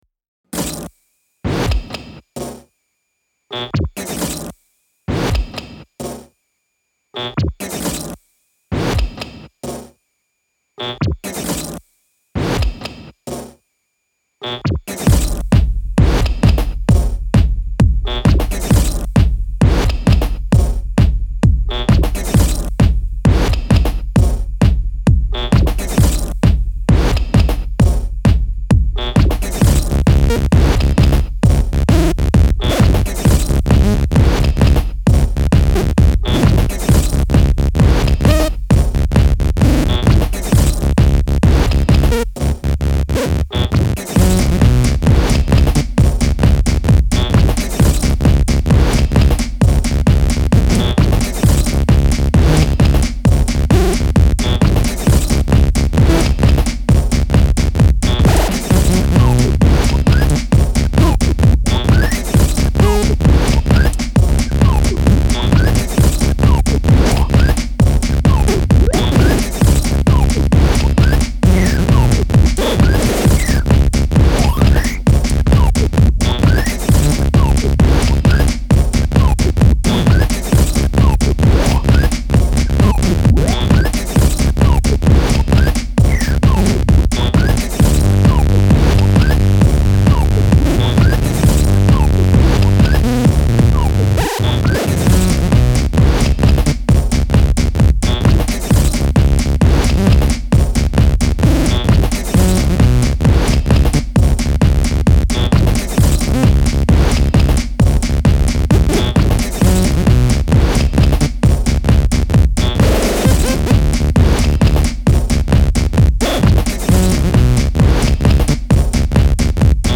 shuffled grooves with strange synthlnes